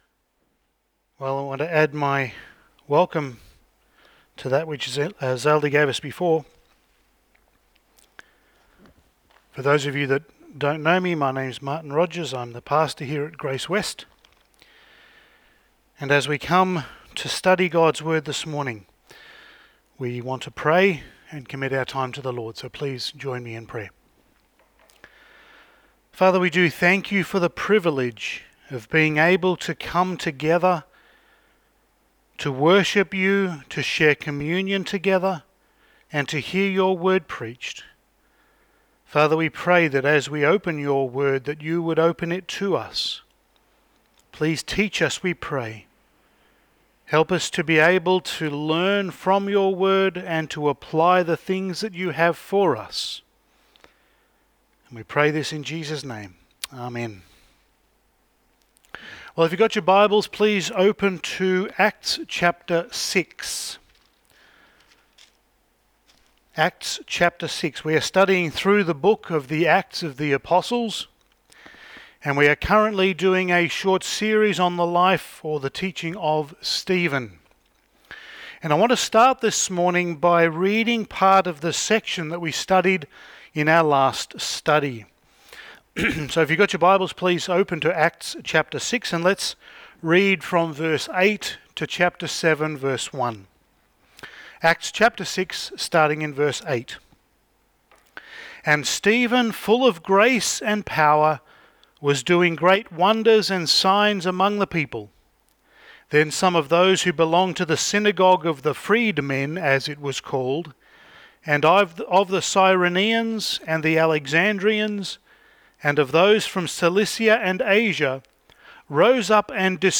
Passage: Acts 7:1-53 Service Type: Sunday Morning